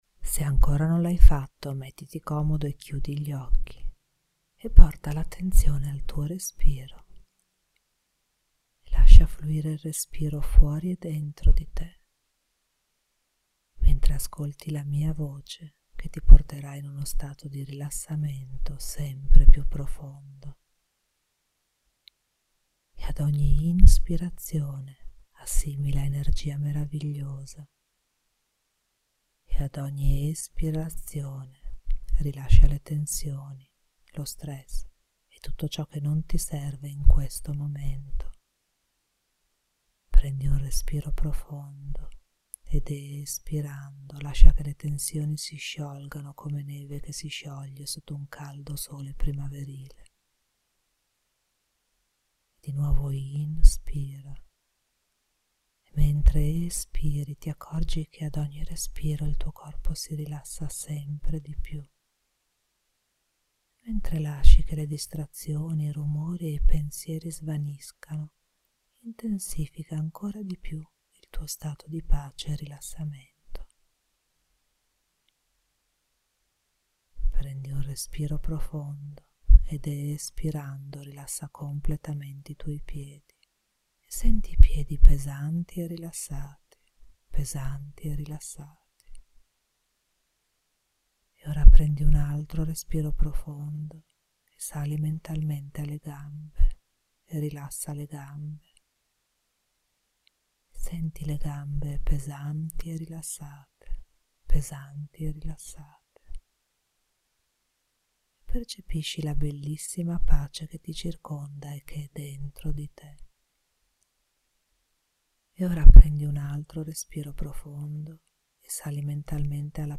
evening meditation
Meditazione_della_sera.mp3